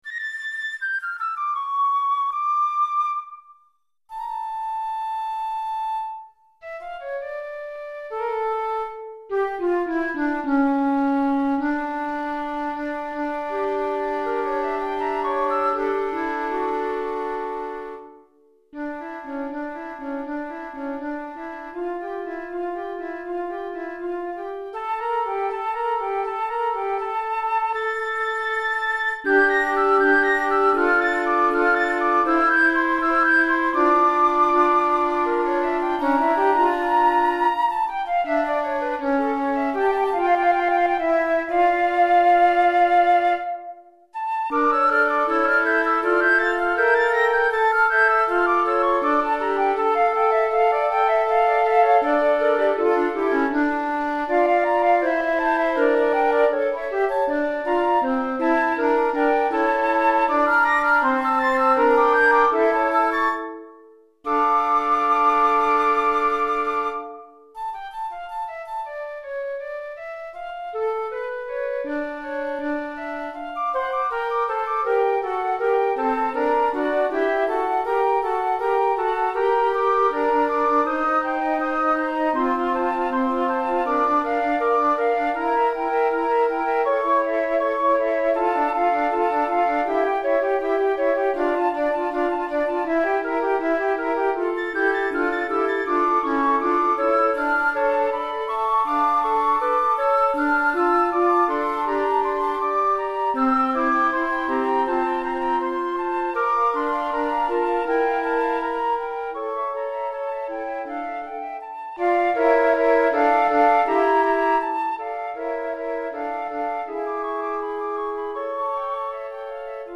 4 Flûtes à Bec